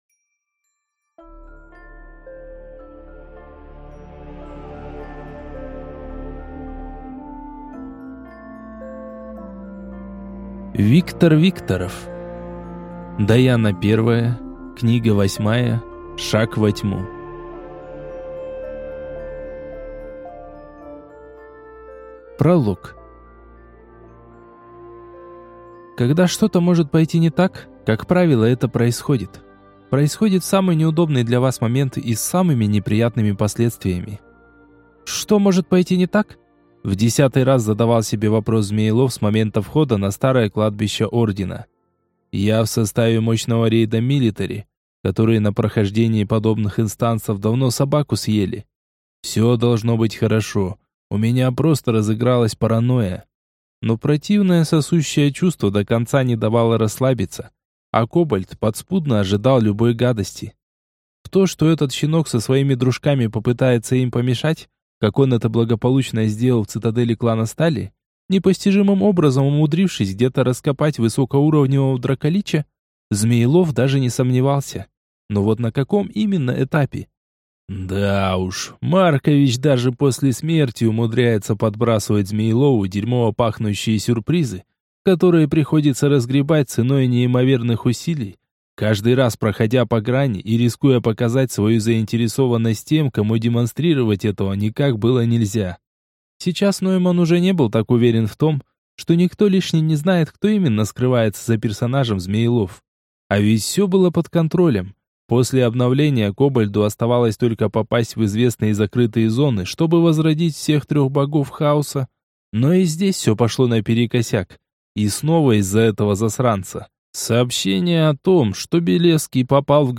Аудиокнига Даяна I. Шаг во Тьму. Том 8 | Библиотека аудиокниг